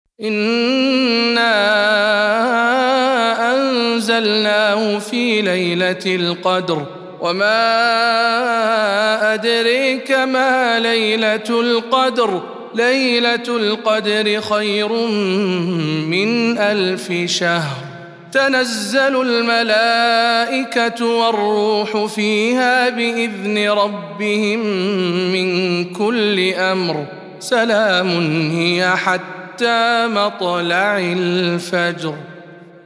سورة القدر - رواية خلف عن حمزة